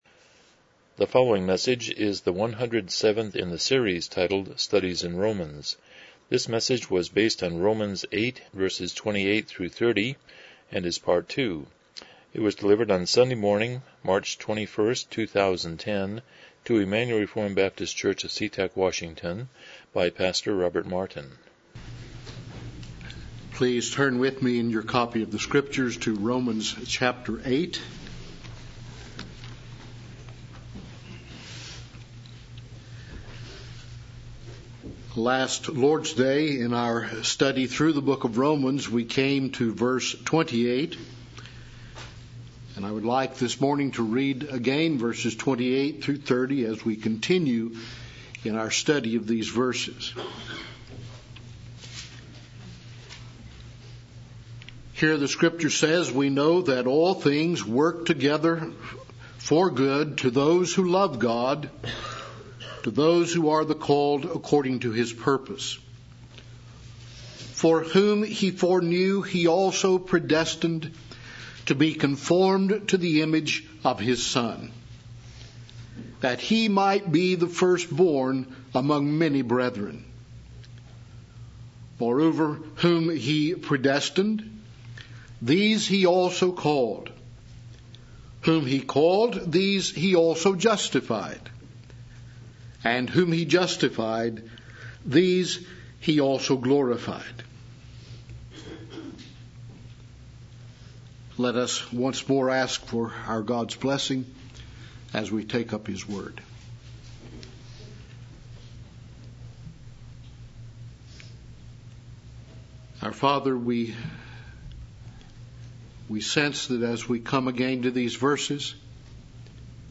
Service Type: Morning Worship